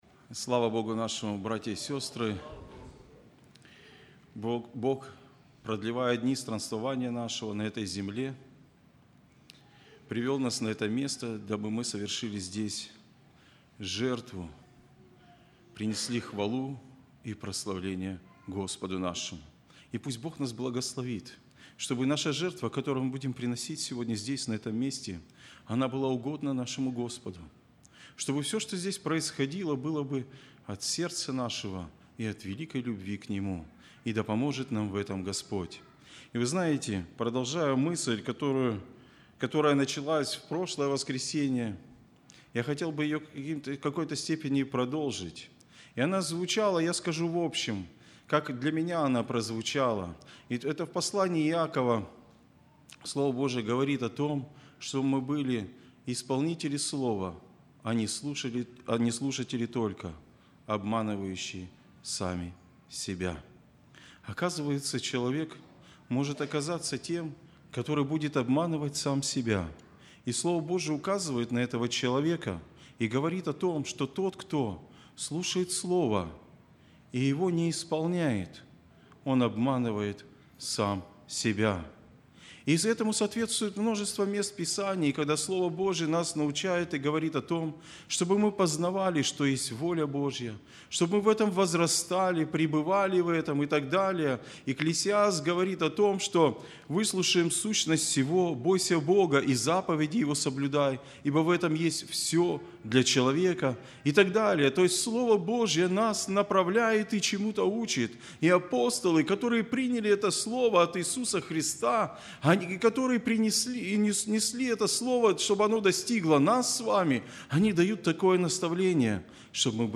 02+Проповедь.mp3